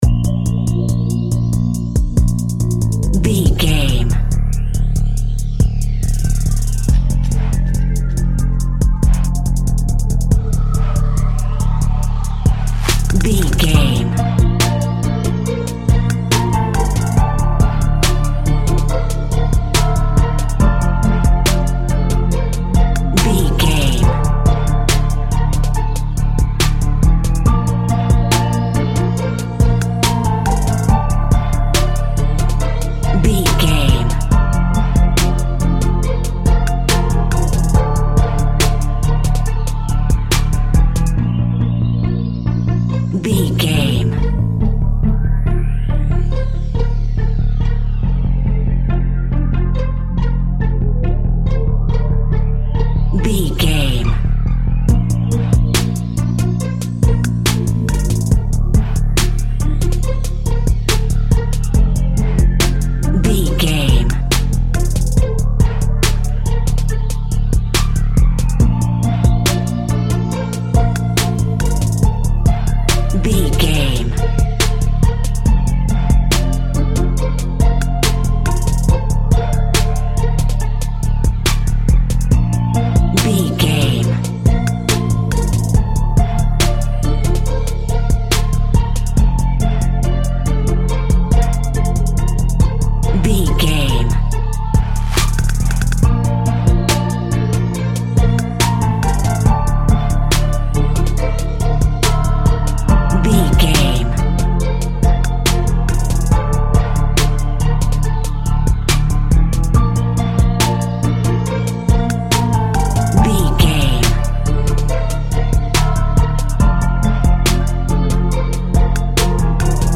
Hip Hop Instrumental Track.
Aeolian/Minor
chilled
laid back
groove
hip hop drums
hip hop synths
piano
hip hop pads